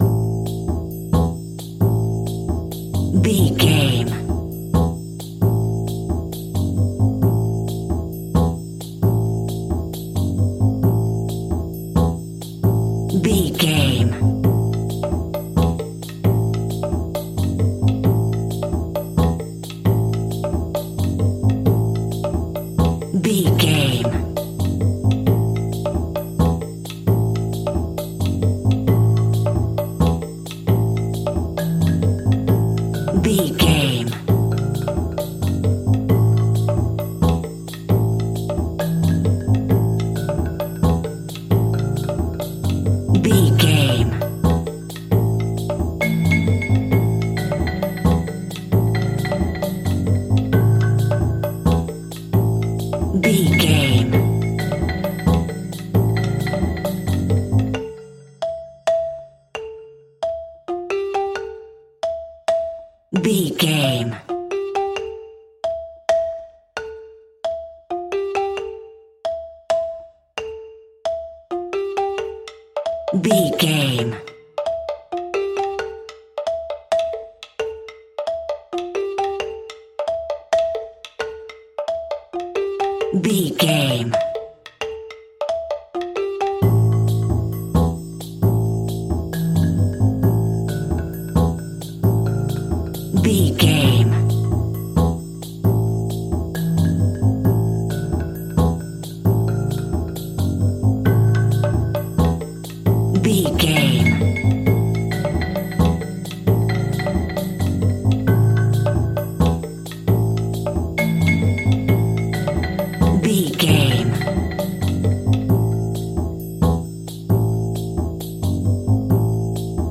Ionian/Major
Fast
childrens music
drums
bass guitar
electric guitar
piano
hammond organ
silly